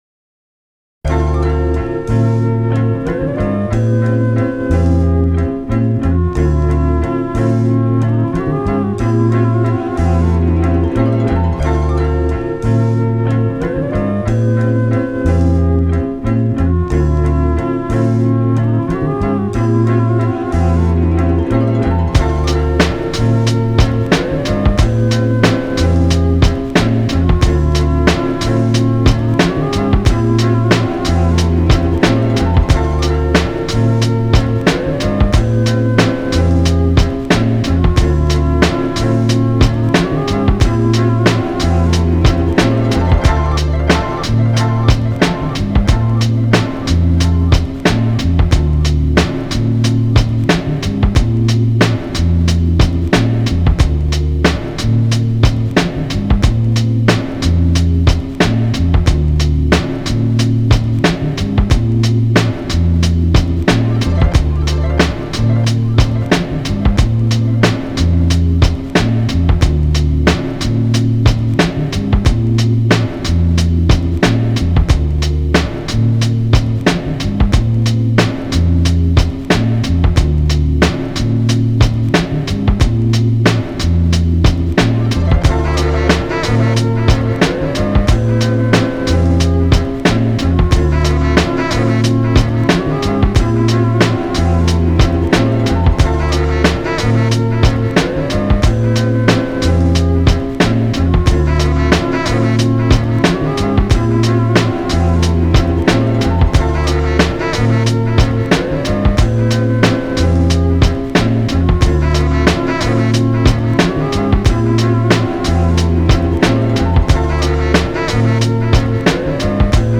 Musique d'ambiance